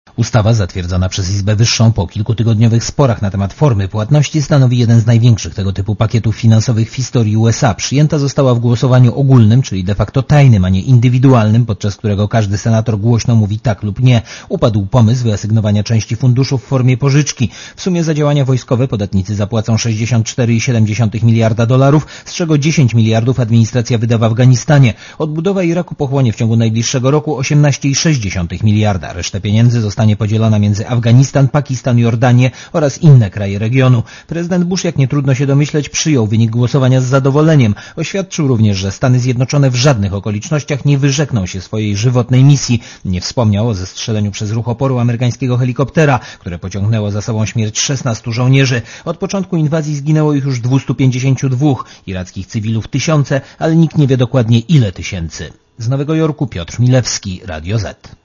Korespondencja z USA (228kb)